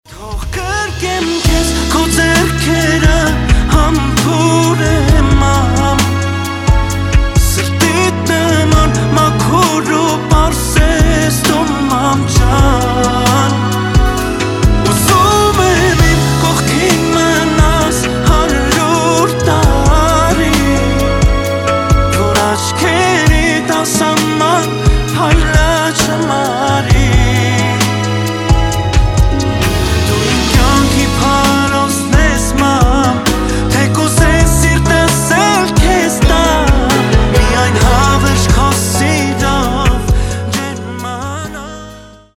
• Качество: 320, Stereo
мужской вокал
медленные
армянские
Красивая армянская песня про маму